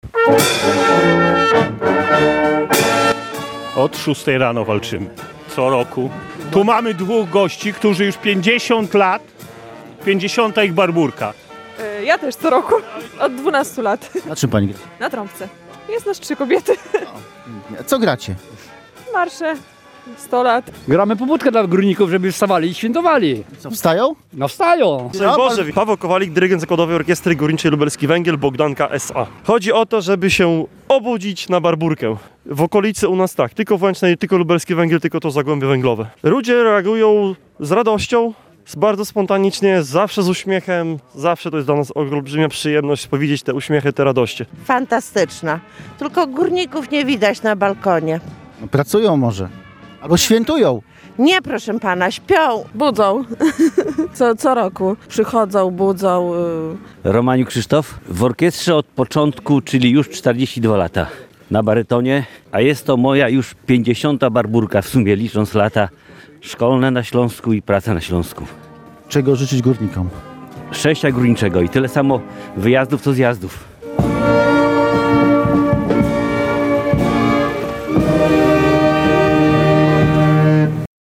Górników z Łęcznej i mieszkańców miasta jak co roku w Barbórkę obudziły dźwięki orkiestry Lubelskiego Węgla Bogdanka.